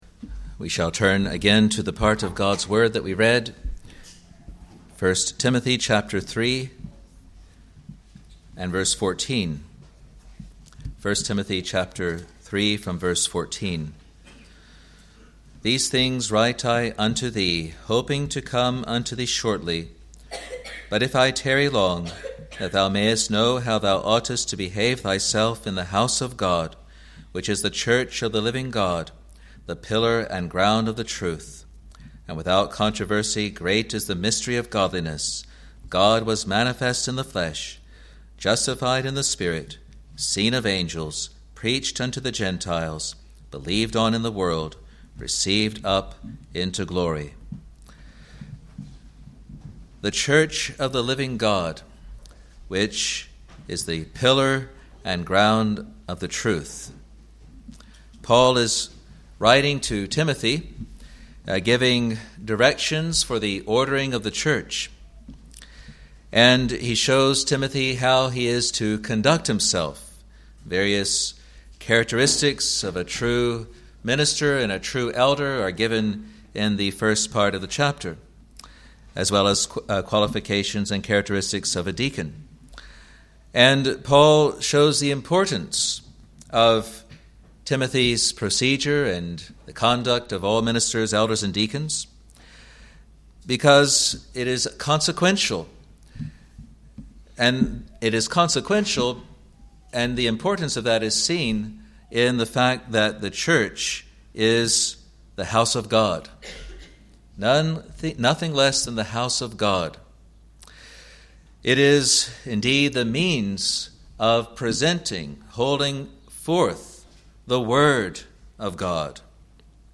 New Years Day Lecture | Free Presbyterian Church of Scotland in New Zealand